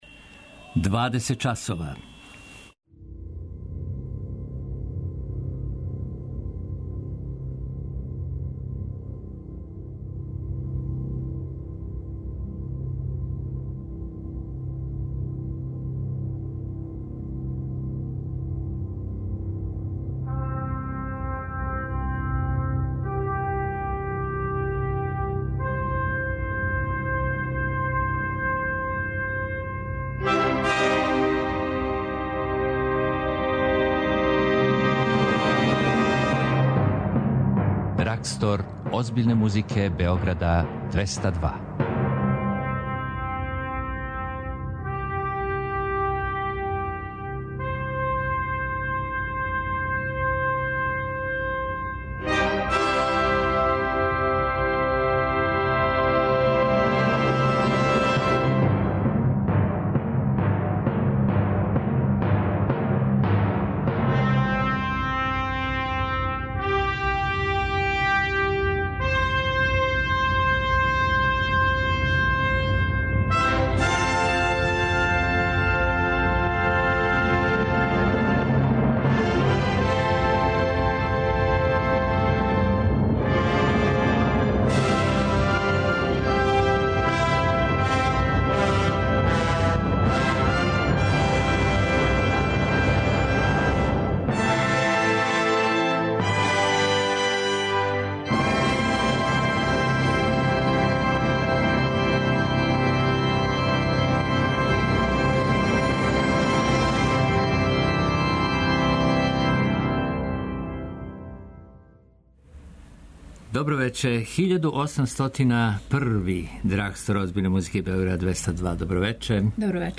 Из вечерашње емисије издвајамо дело Лабуд из Туонеле Јана Сибелијуса, Росинијев дует за виолончело и контрабас, композицију Клеопатра за соло виолину турског пијанисте и композитора Фазила Саја као и песме за глас и клавир Сергеја Рахмањинова у интерпретацији славног челисте Мише Мајског!
На концерту у галерији Артгет након 22ч слушаћемо Бриџову Фантазију и Сен-Сансов трио за клавир, виолину и виолончело у извођењу студената из Србије, Хрватске и Немачке.